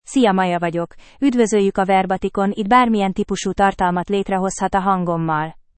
MayaFemale Hungarian AI voice
Maya is a female AI voice for Hungarian (Hungary).
Voice sample
Listen to Maya's female Hungarian voice.
Female
Maya delivers clear pronunciation with authentic Hungary Hungarian intonation, making your content sound professionally produced.